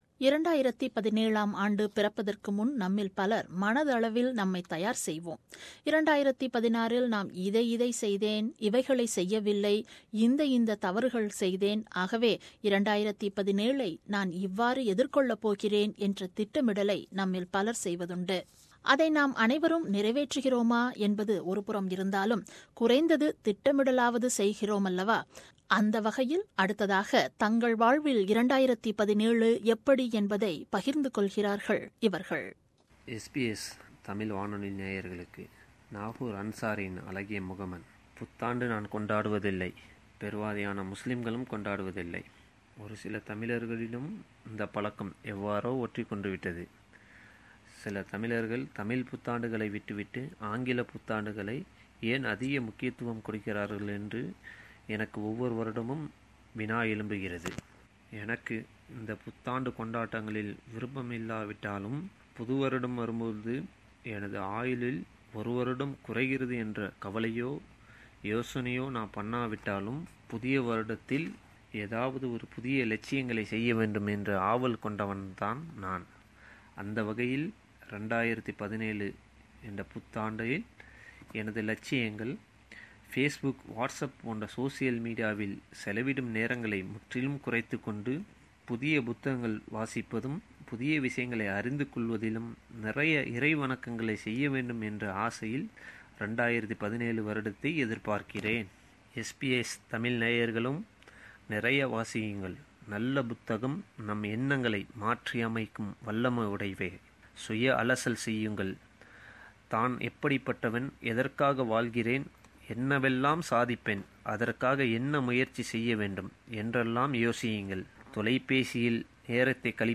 How am I going to face 2017 and what are all my plans - voxpop